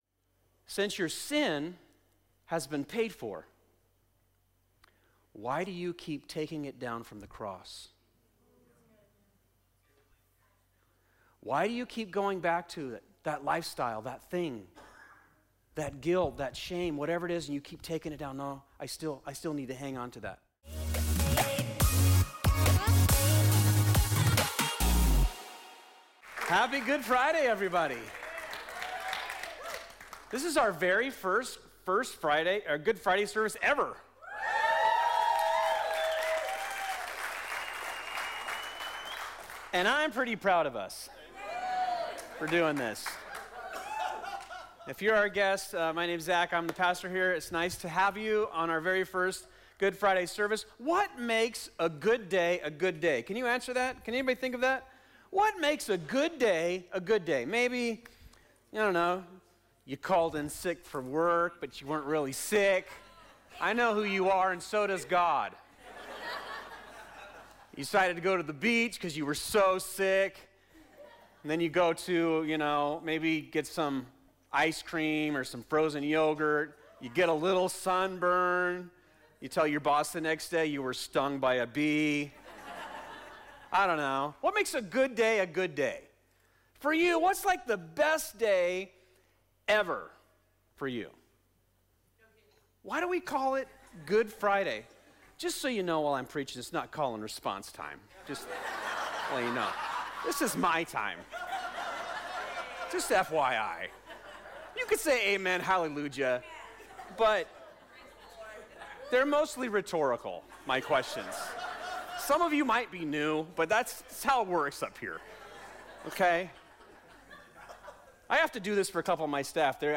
This is part 5 of "Last Words," our sermon series at Fusion Christian Church where we examine the final seven sayings of Jesus before he died.